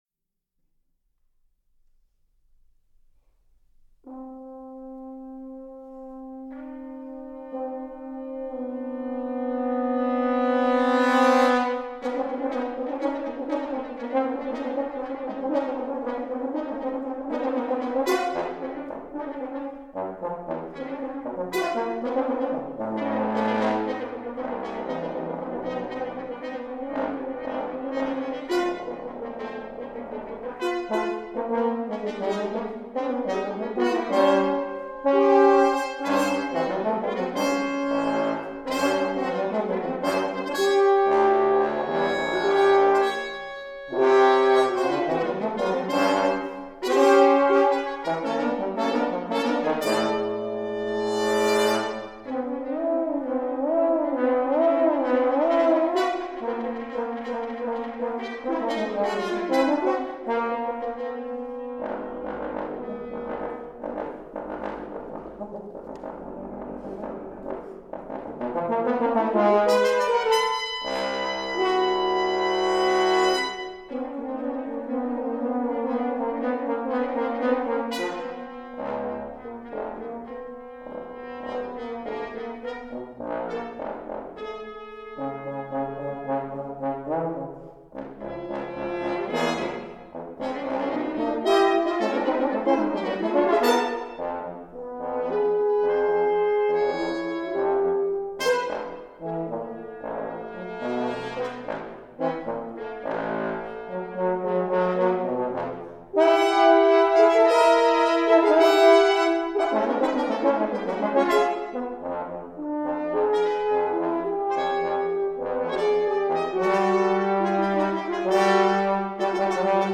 trumpet
trombone